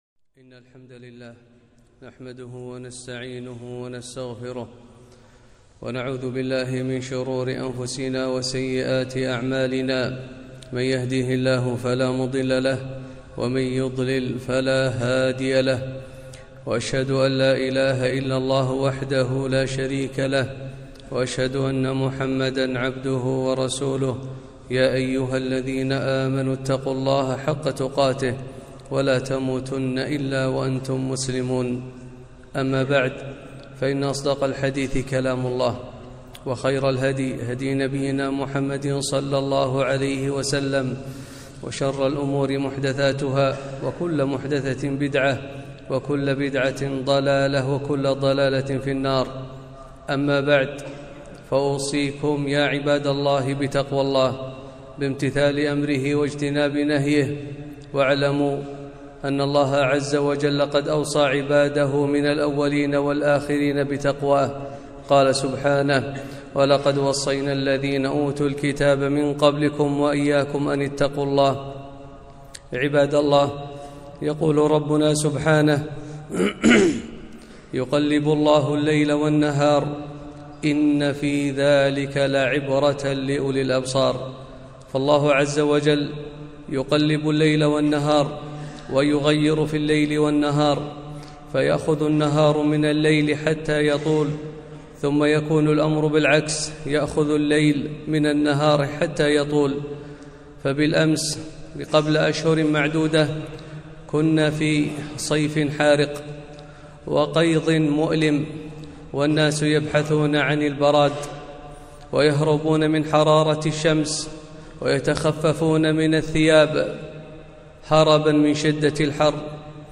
خطبة - وقفات مع فصل الشتاء